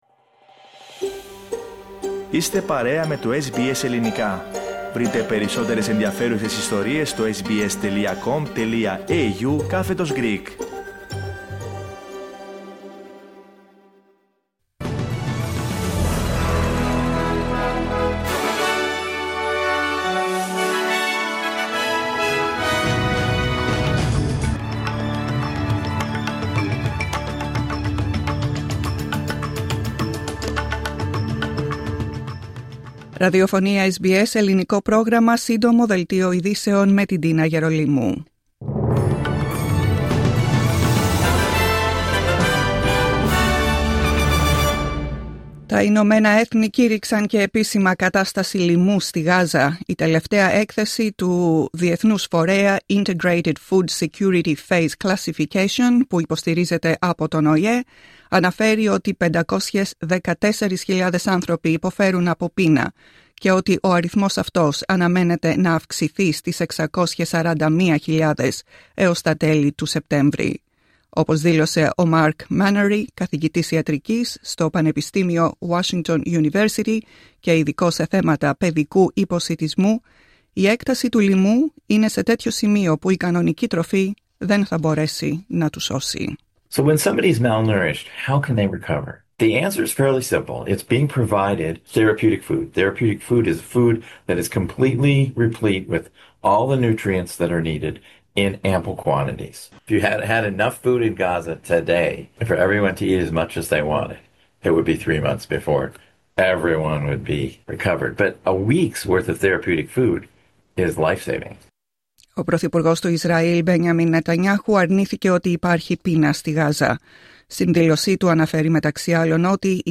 Δελτίο ειδήσεων Σάββατο 23 Αυγούστου 2025
Σύντομο δελτίο ειδήσεων απ΄το Ελληνικό Πρόγραμμα της SBS.